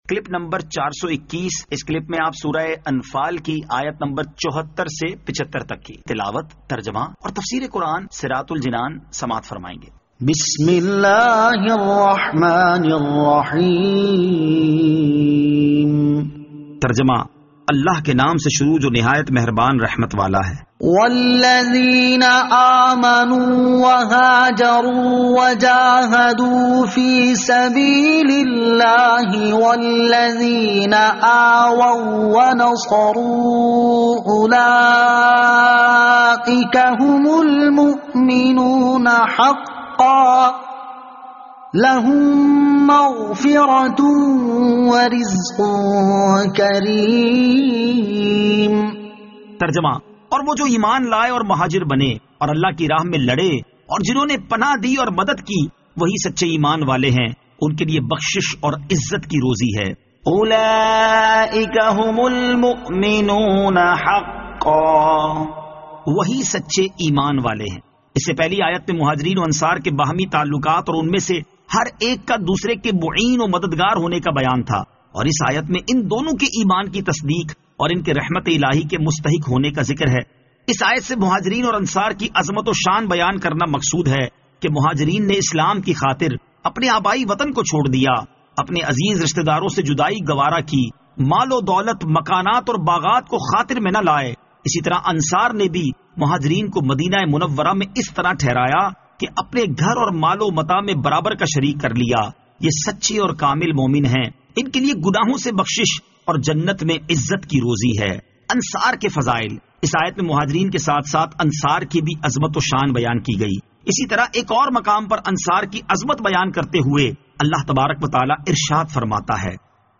Surah Al-Anfal Ayat 74 To 75 Tilawat , Tarjama , Tafseer